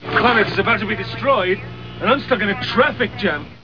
All sounds are of Paul McGann from the telefilm, Doctor Who.
Sounds were originally sampled at 22 kHz, 16-bit mono with GoldWave, then resampled to 11 kHz, 8-bit mono to reduce their file size.